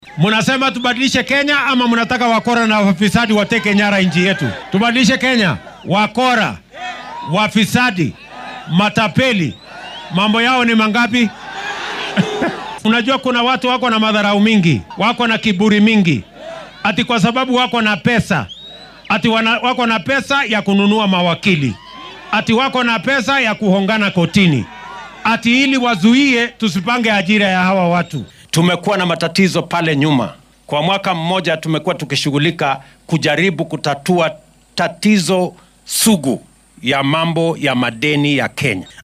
Xilli uu ku sugnaa deegaanka Kuresoi ee ismaamulka Nakuru ayuu hoosta ka xarriiqay in shaqaalaha waaxdan ay caqabad ku noqdeen hirgelinta mashaariicda horumarineed ee dowladda sida caafimaadka iyo guriyeynta.
William-Ruto-1.mp3